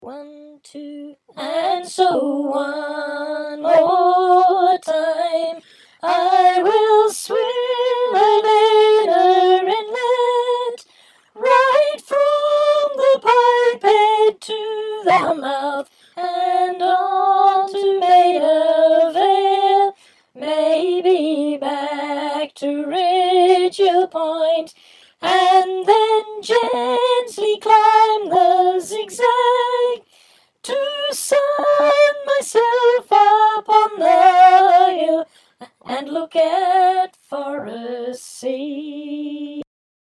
forrest-sea-chorus-sop.mp3